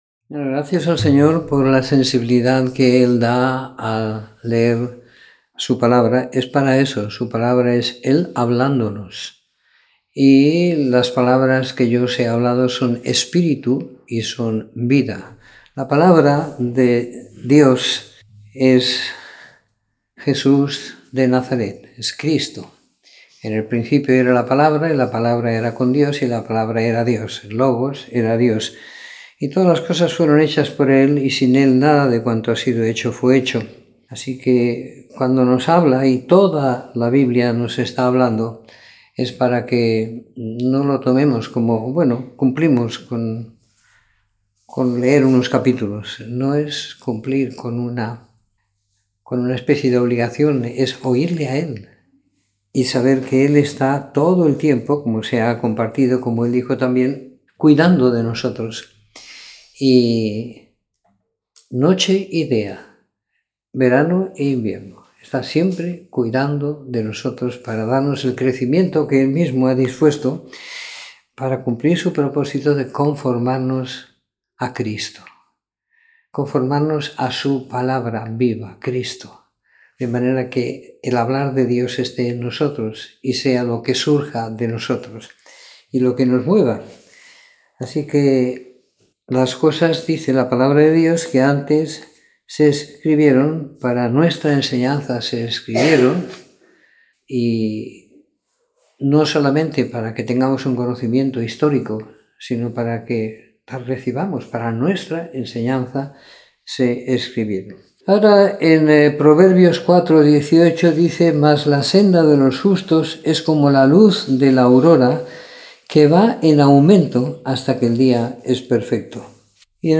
Comentario en el libro de Jueces siguiendo la lectura programada para cada semana del año que tenemos en la congregación en Sant Pere de Ribes.